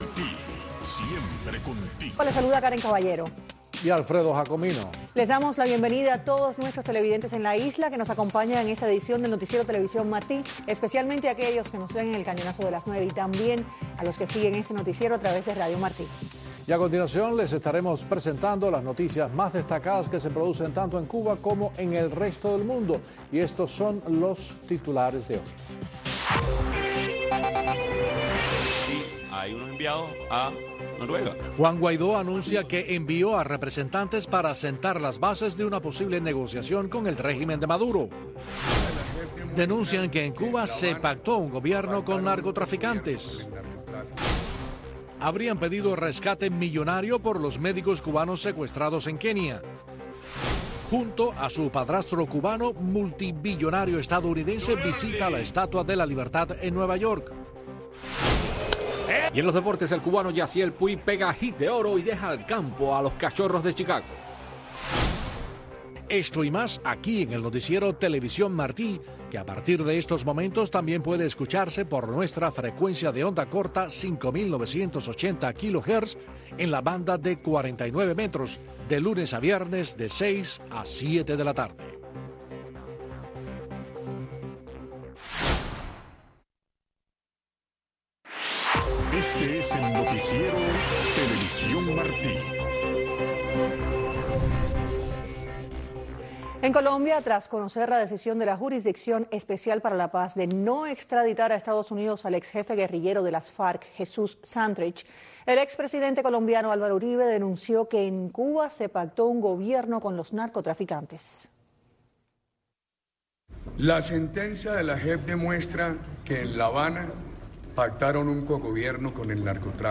Noticiero de TV Martí